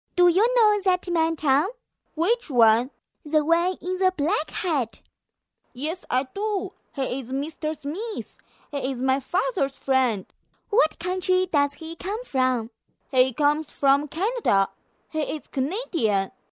配音风格： 亲切 欢快 优雅 时尚 甜美